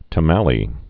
(tə-mălē, tŏmălē)